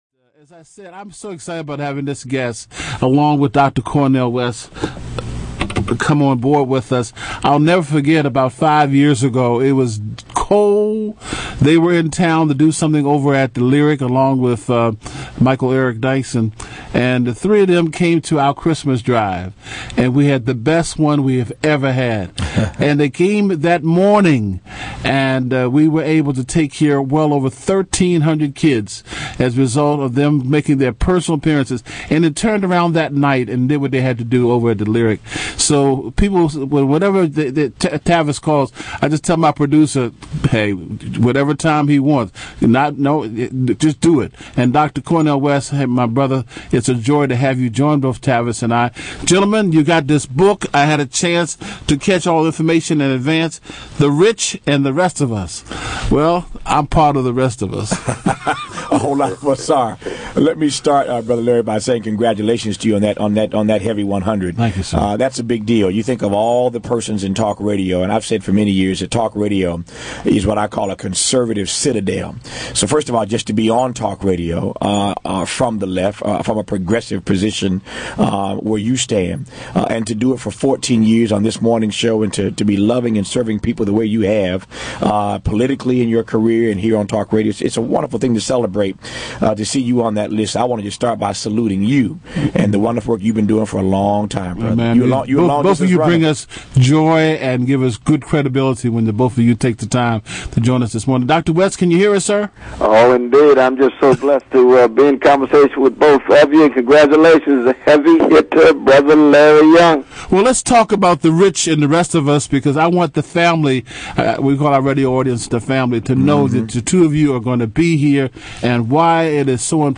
Larry Young interviews Tavis Smiley & Dr.Cornel West (AUDIO)
Today Tavis Smiley(in-studio) and Dr. Cornel West (via phone) stopped by The Larry Young Morning Show to discuss the new book entitled